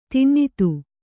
♪ tinnitu